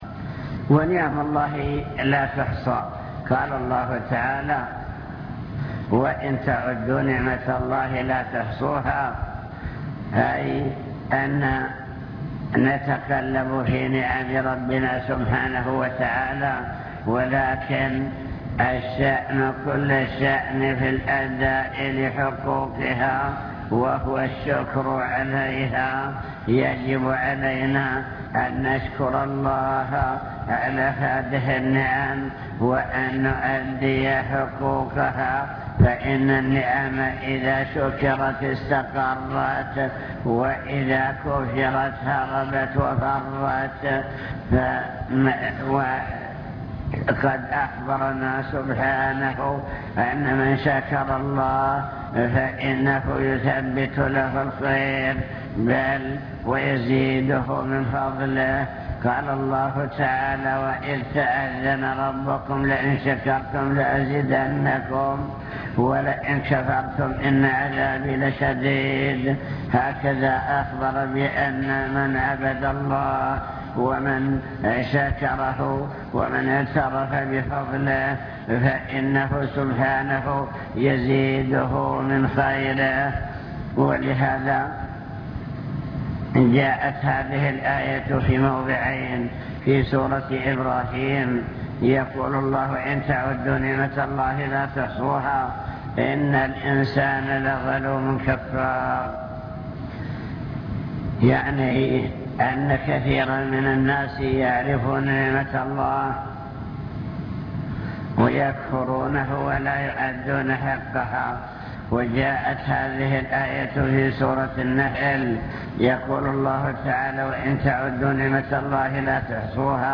المكتبة الصوتية  تسجيلات - محاضرات ودروس  محاضرة بعنوان شكر النعم (2) نعم الله تعالى وعظمها